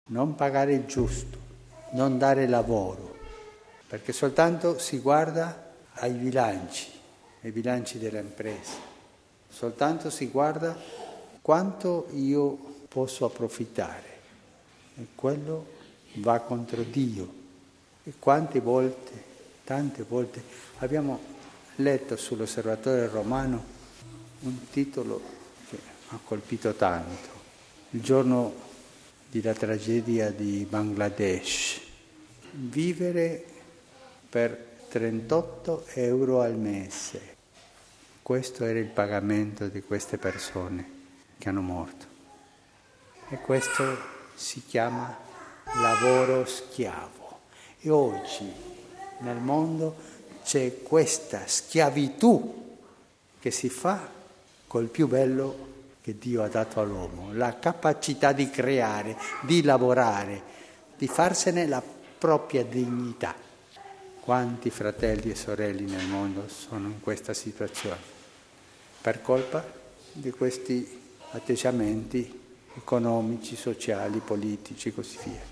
Det sa påven i mässan i Santa Martakapallet till minnet av Josef arbetaren.